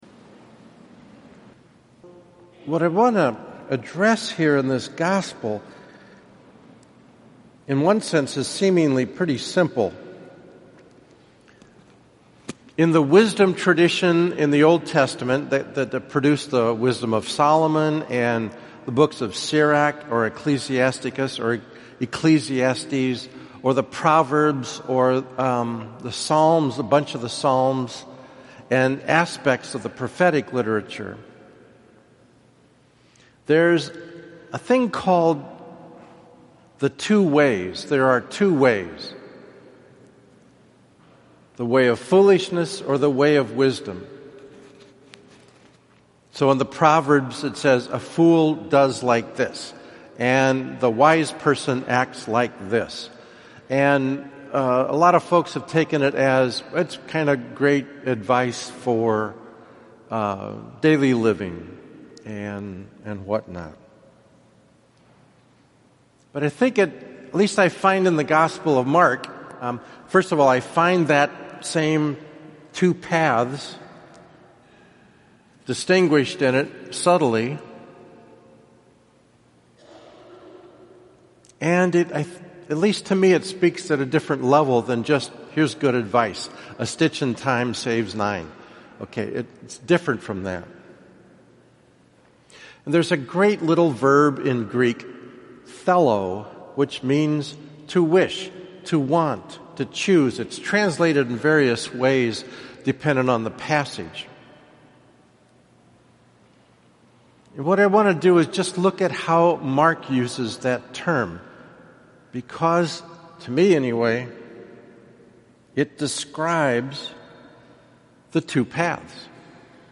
Twenty-Seventh Sunday of Ordinary Time